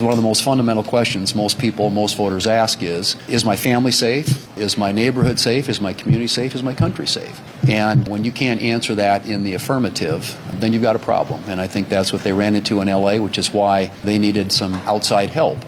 In a press conference on Capitol Hill Tuesday, Thune criticized how local officials have been dealing with the situation.